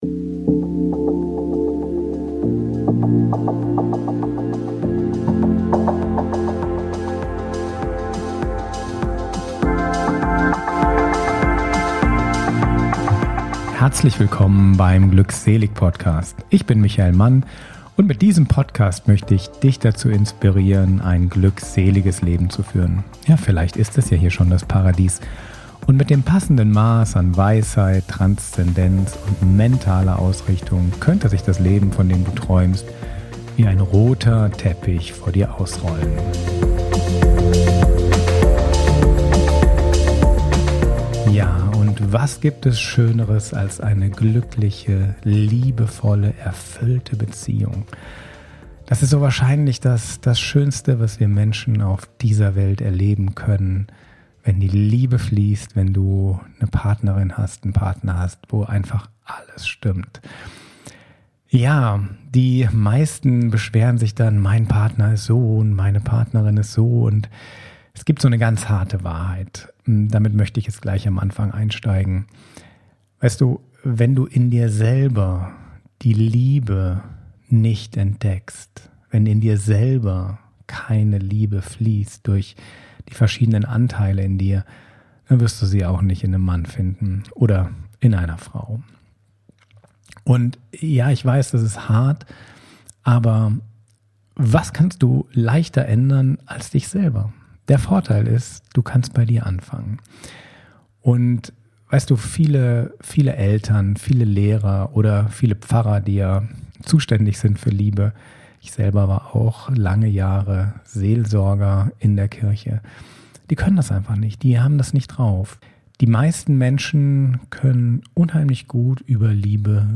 Selbstliebe MEDITATION ~ glückselig Podcast
Beschreibung vor 1 Jahr Glückselig Podcast – Love Flows & Selbstliebe Meditation In dieser Episode tauchen wir tief in die Kunst, die Liebe in dir und um dich herum wieder fließen zu lassen ein. Dich erwartet eine kraftvolle Selbstliebe-Meditation, die dir hilft, dein Herz zu öffnen, und dich selbst in deiner ganzen Fülle anzunehmen.